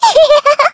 Taily-Vox_Happy2_kr.wav